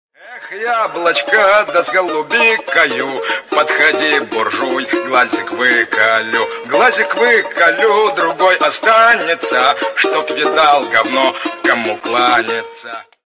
на балалайке